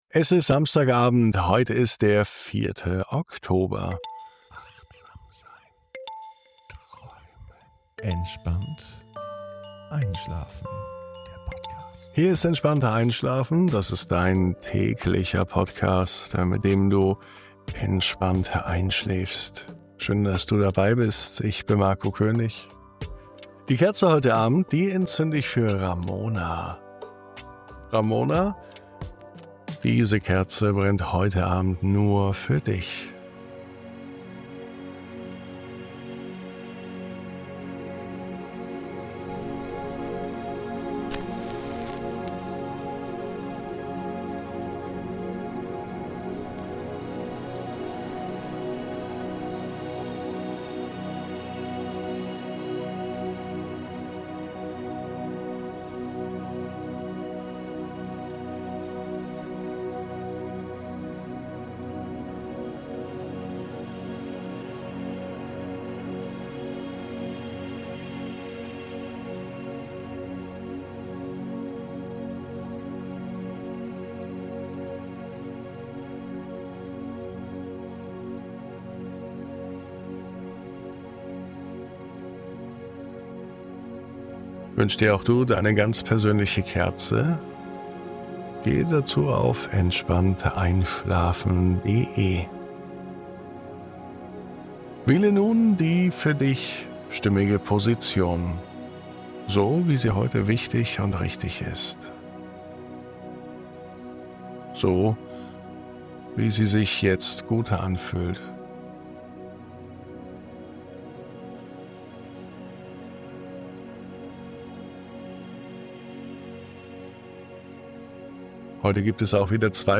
1004_MUSIK(1).mp3